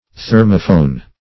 thermophone - definition of thermophone - synonyms, pronunciation, spelling from Free Dictionary
Thermophone \Ther"mo*phone\, n. [Thermo- + phone.]